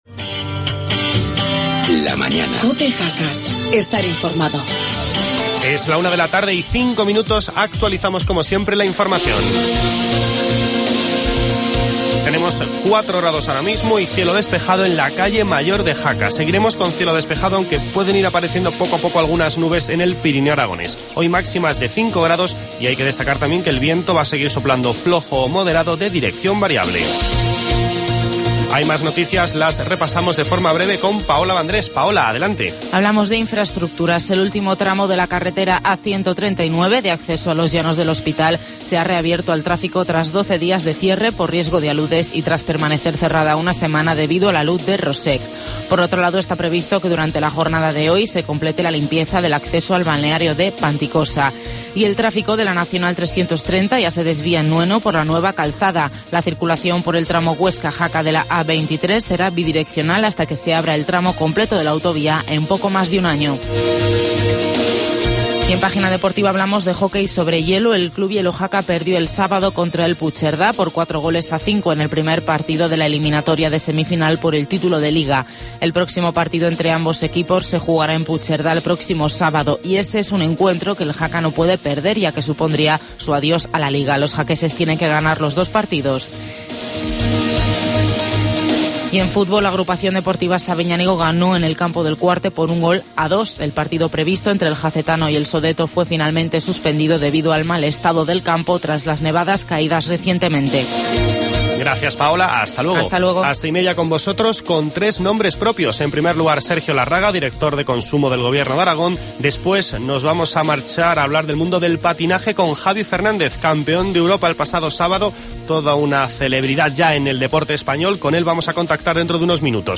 Charlamos con el campeón de Europa de patinaje artístico Javi Fernández.